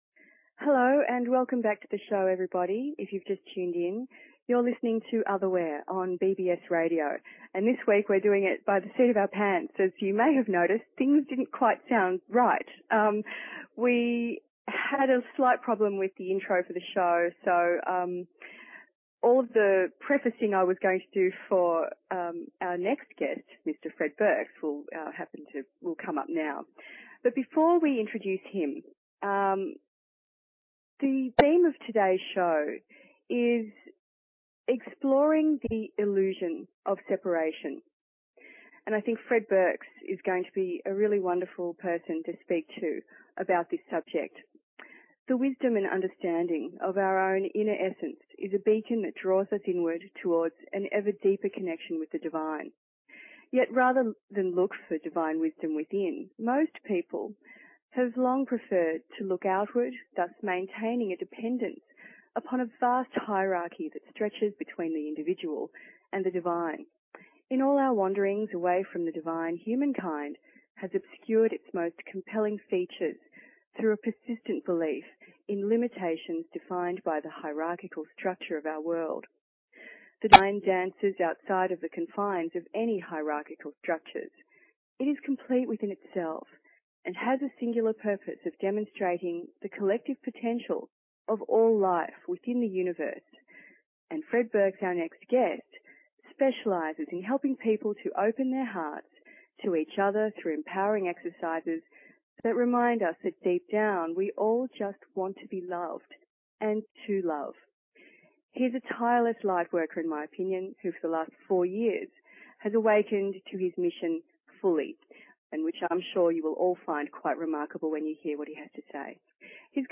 Talk Show Episode, Audio Podcast, Otherware and Courtesy of BBS Radio on , show guests , about , categorized as